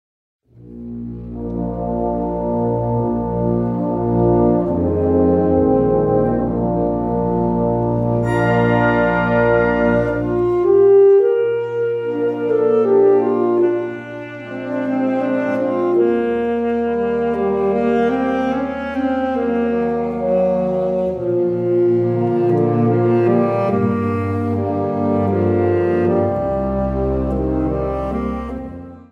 Categoria Concert/wind/brass band
Sottocategoria Musica per concerti
Instrumentation Ha (orchestra di strumenti a faito)